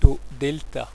tÕ dšlta[d]
La prononciation ici proposée est la prononciation qui a cours actuellement, en milieu scolaire, en France.